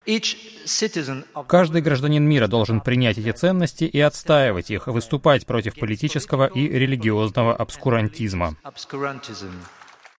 Говорит главный редактор "Шарли Эбдо" Жерар Биар